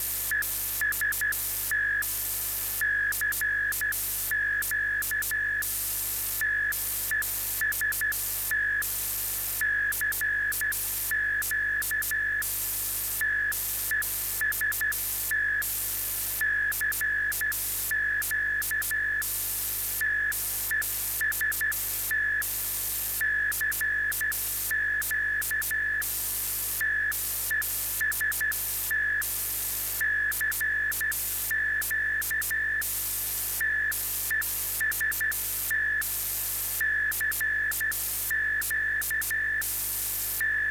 Hörprobe einer Morseaussendung aufgenommen mit einem Kontrollempfänger AOR3000